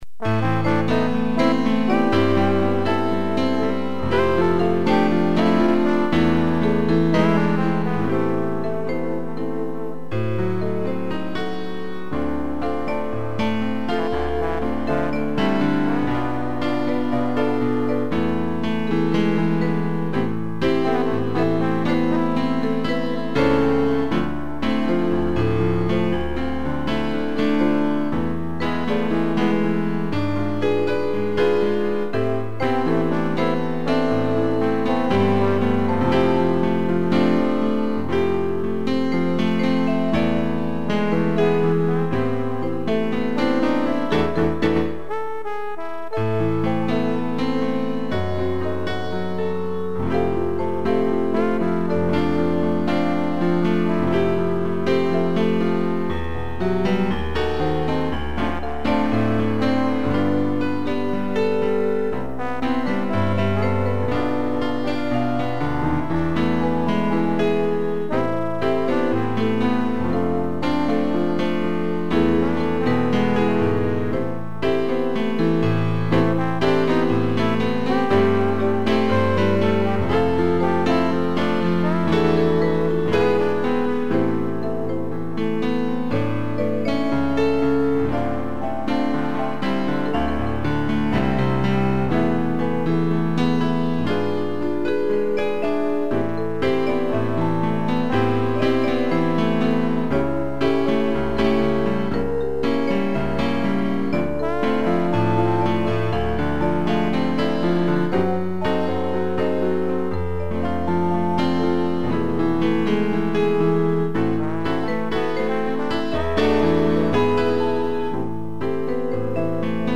piano, vibrafone e trombone
instrumental